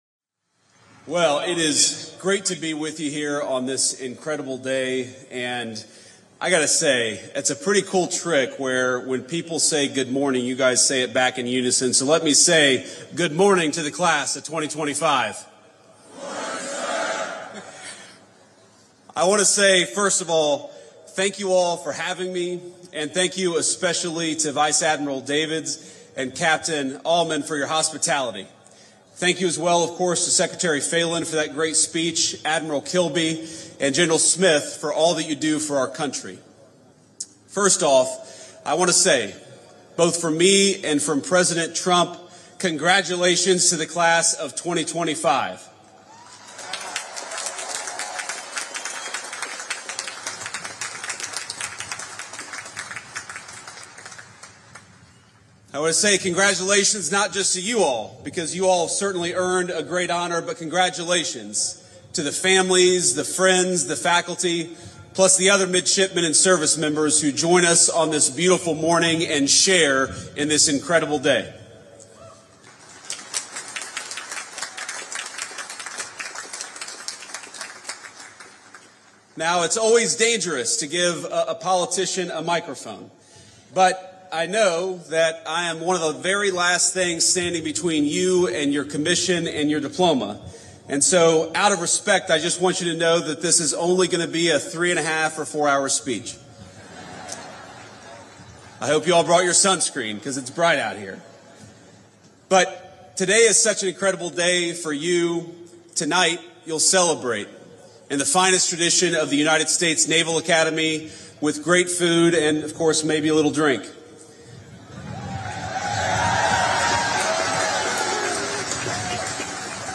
JD Vance: Commencement Address to the 2025 Graduating Class of the U.S. Naval Academy (transcript-audio--video)